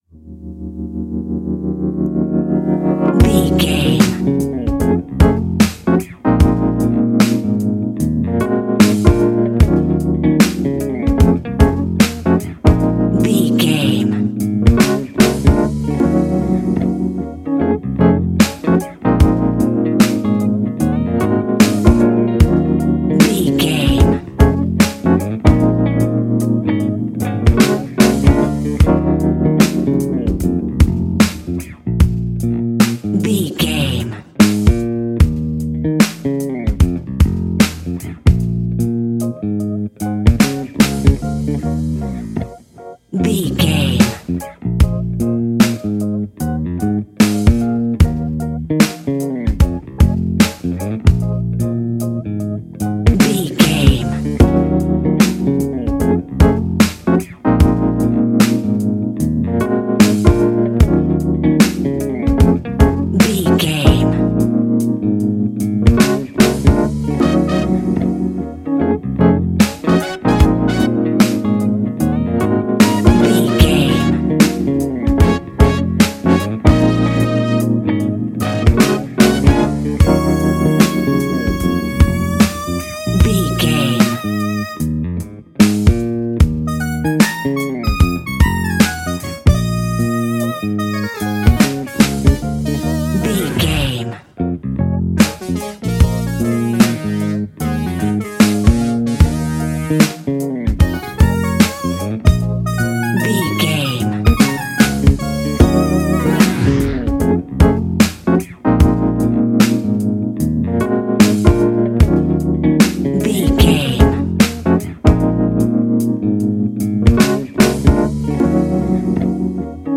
Fast paced
Uplifting
Ionian/Major
D♯